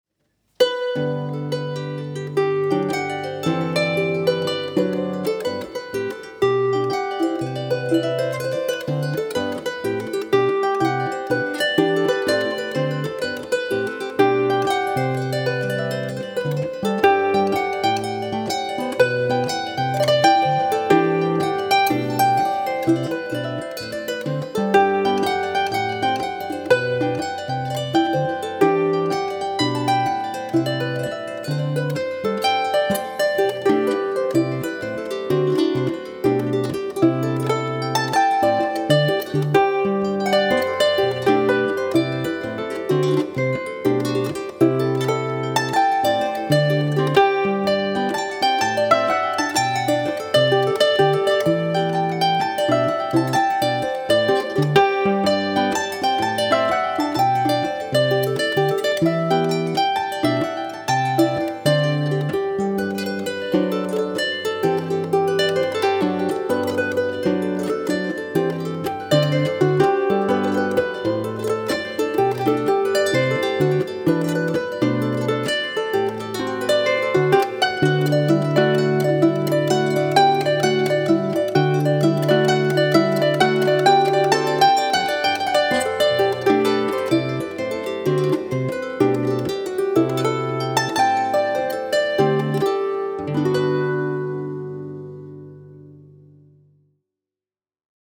String Musicians
He is an absolute master of the Harp and Clarsach (small Scottish/Irish Harp),